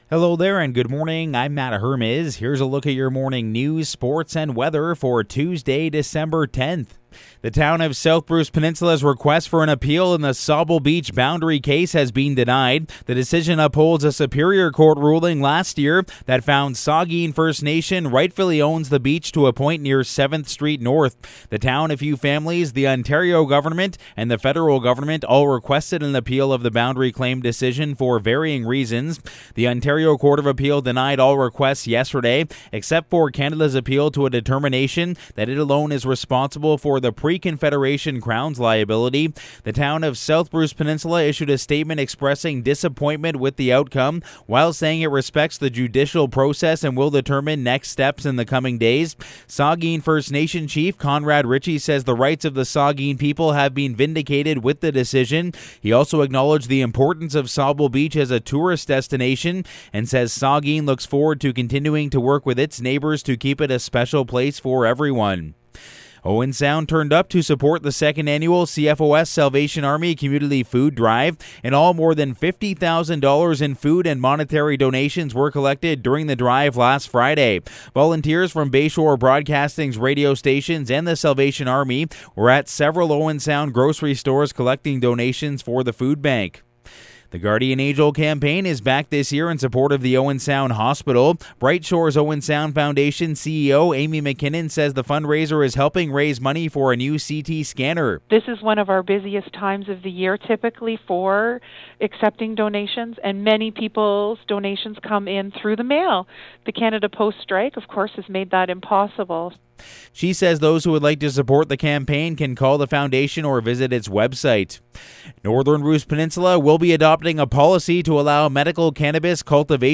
Morning News – Tuesday, December 10